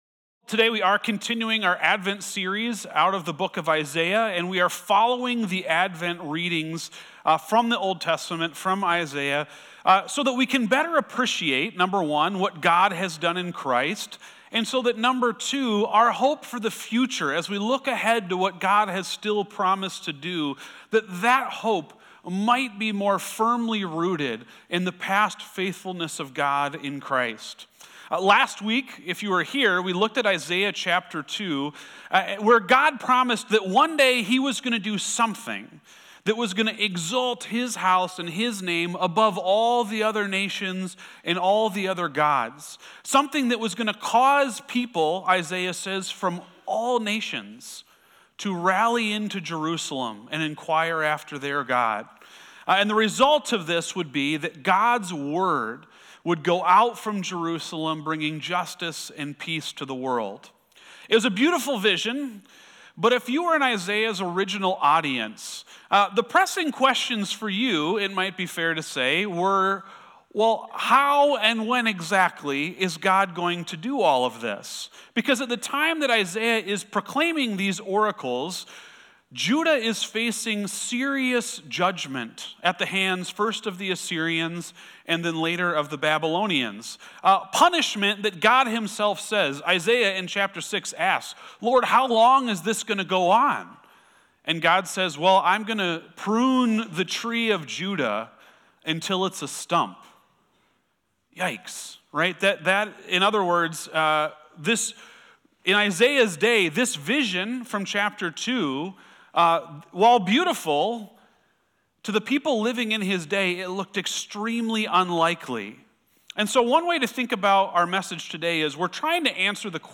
sermon-audio-12-7-25.mp3